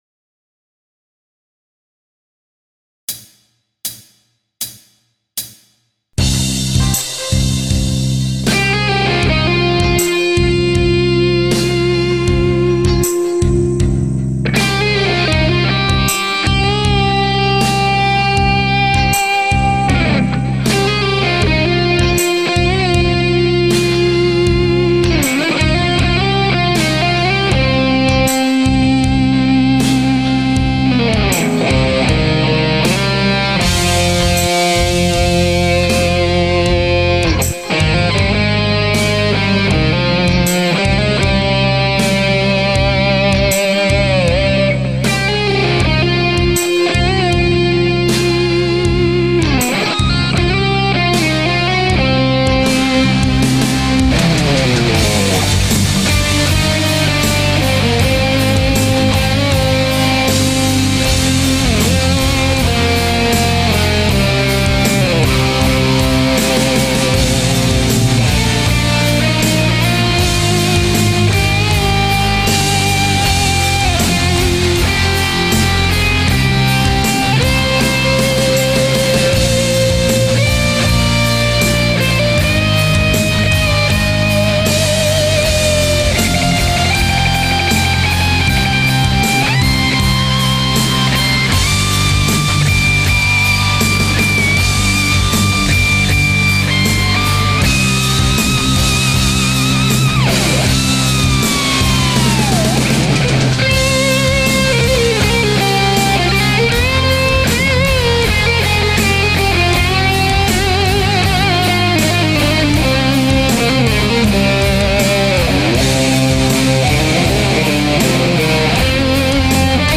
I finally got around to adding the solo today
I will probably remix it again, cause I have some levels that are not right
Unfortunately, the bass and drums are a pre mixed backing track
I think that may be some of the best phrasing and vibrato playing that I've heard you do.
The mix on it is great!
I started with a backing track of bas and drums